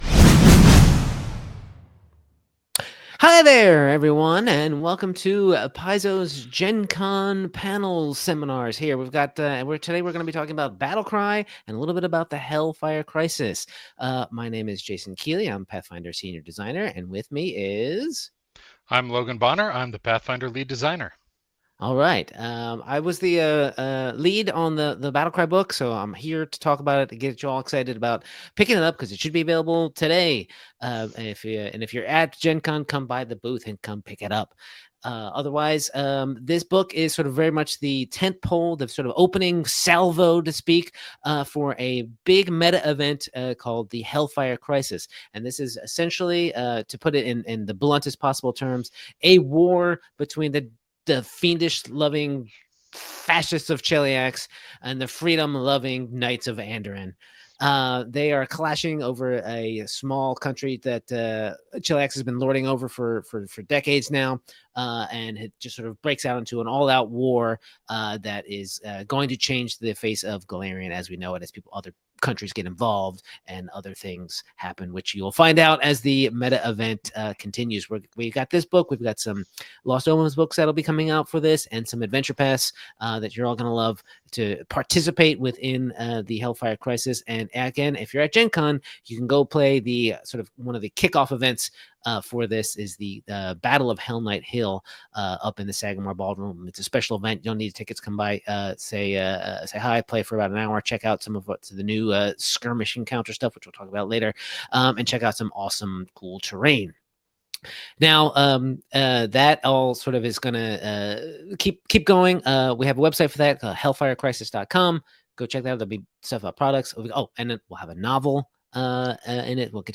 From the Gen Con 2025 Starfinder Launch Panel, shared with permission.